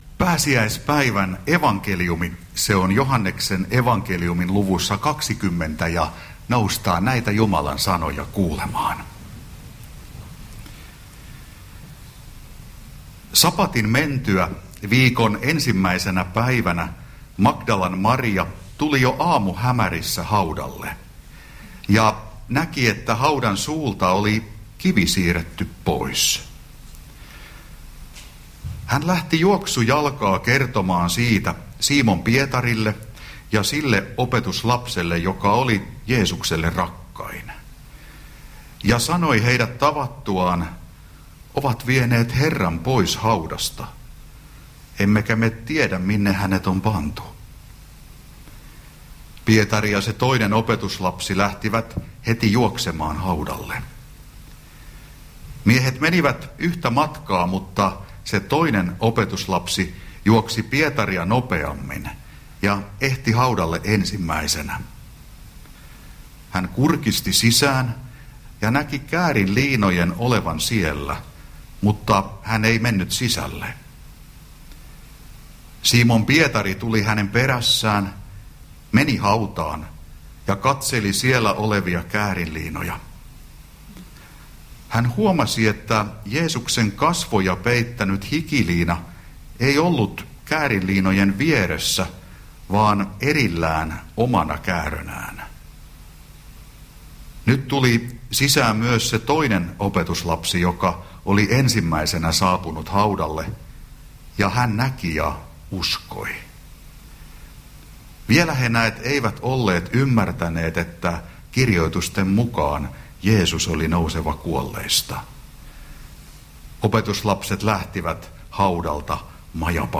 saarna Karkussa pääsiäispäivänä